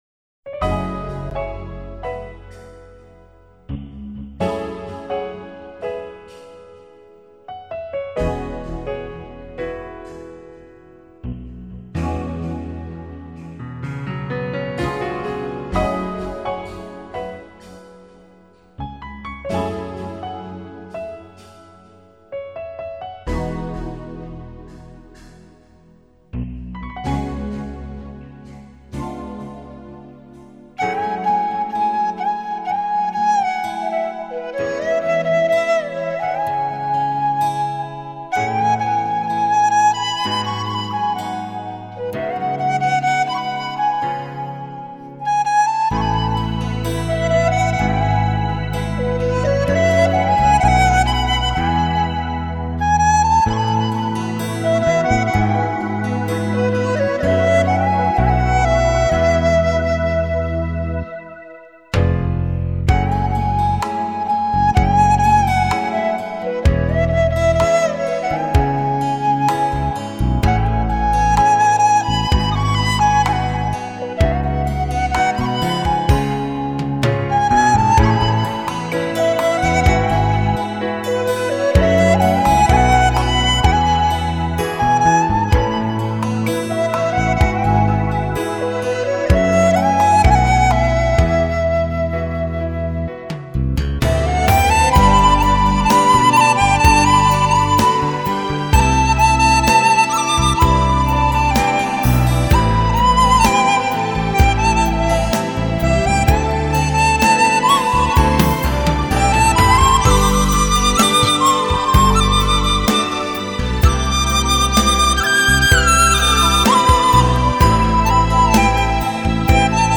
这个质量挺好的